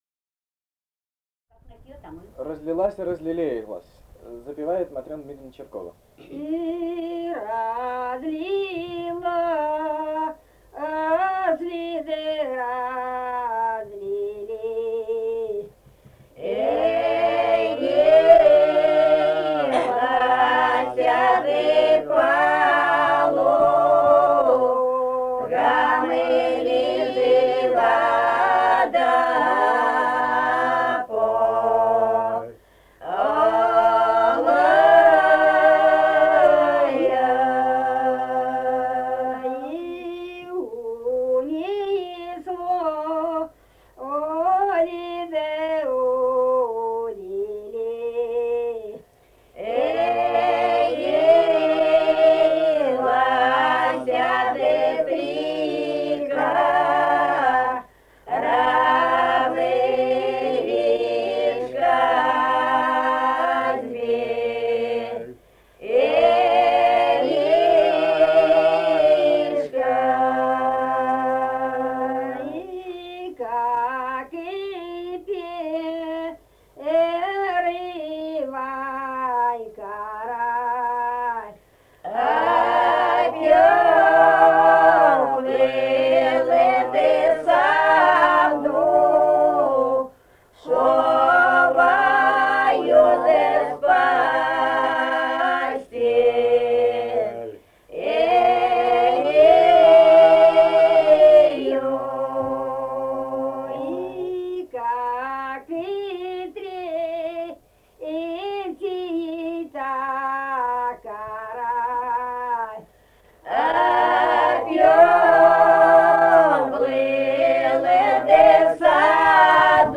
Этномузыкологические исследования и полевые материалы
Самарская область, с. Кураповка Богатовского района, 1972 г. И1319-27